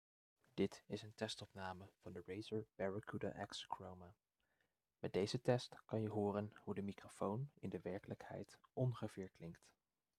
Opnamekwaliteit
De gesprekskwaliteit is écht goed, en je hoort er niet aan af dat het om een ‘middenklasse’-headset-microfoon gaat.
De accuduur is indrukwekken, en hoewel de microfoon wat aan de stille kant is, is de opnamekwaliteit verrassend goed.